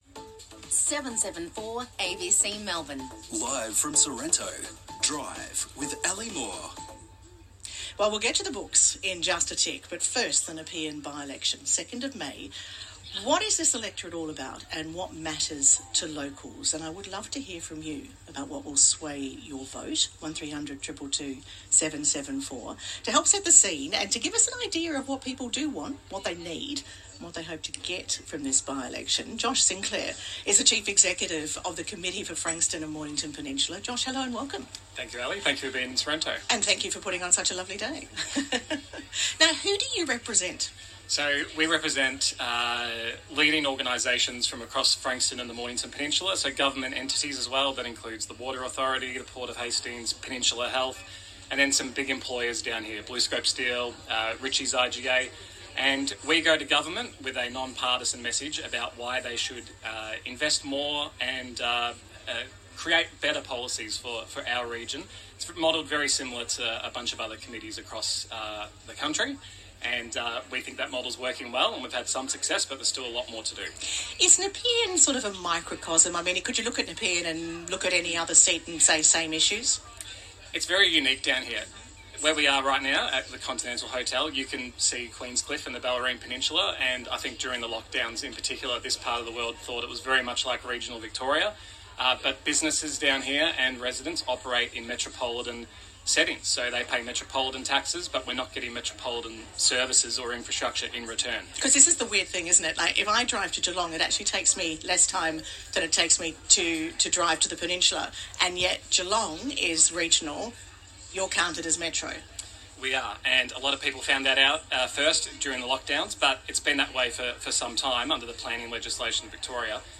ABC Radio Melbourne Drive - Live from Sorrento